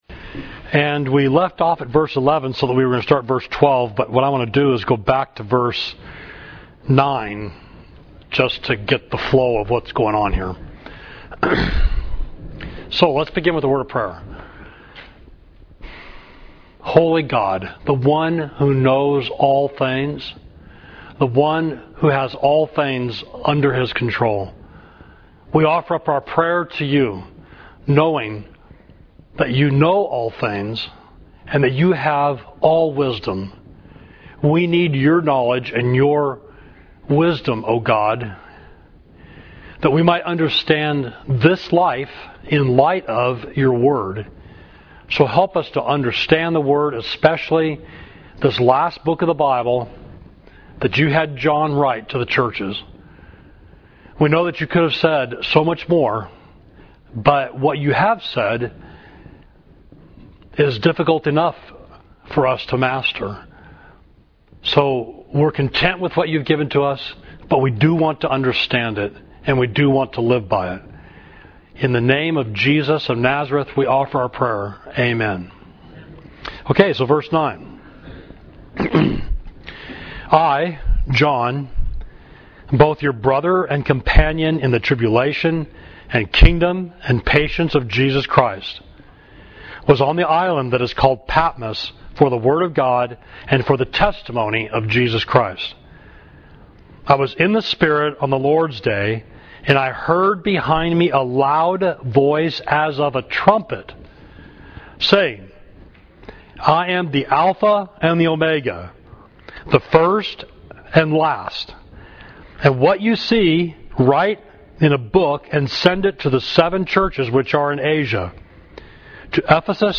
Class: The Glorified Christ, Revelation 1.9–20